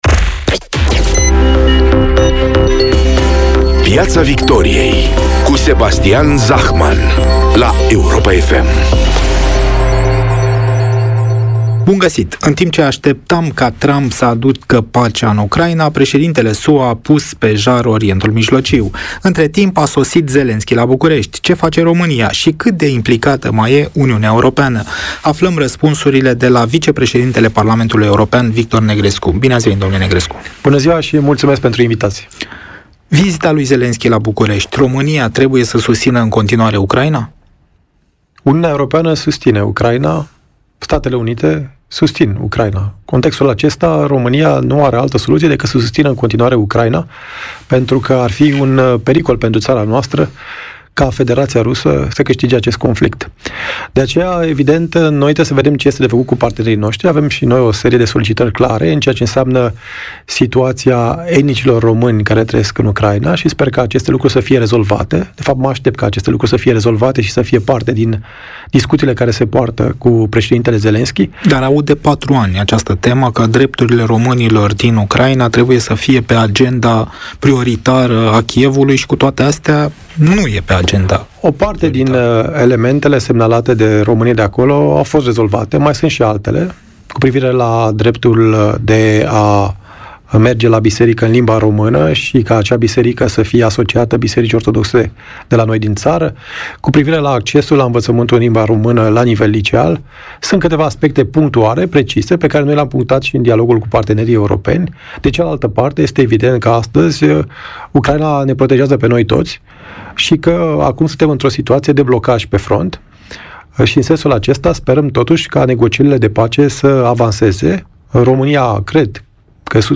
Ioana Ene Dogioiu vorbește cu invitata sa, Adriana Săftoiu, expert în comunicare, fost consilier prezidențial despre anul electoral, comasări, dar și despre “secretul” lui Iohannis.